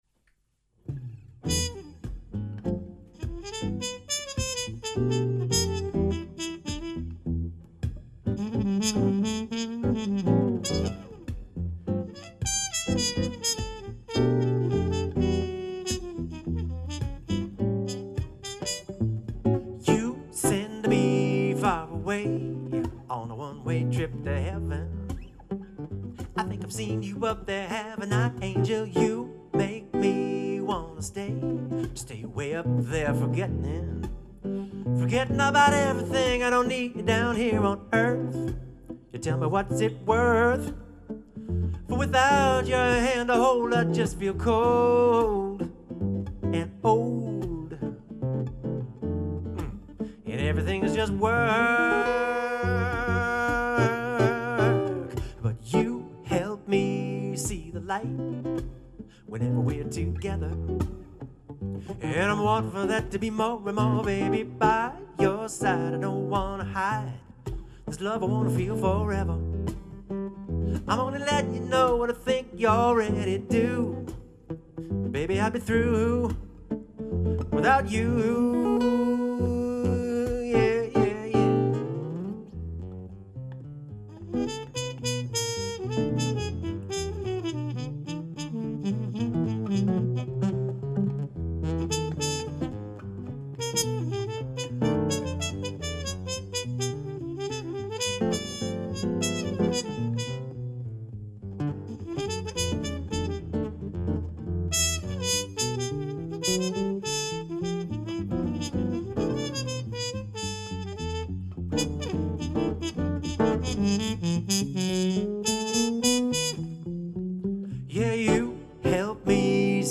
swinging singing (+trumpet)
guitar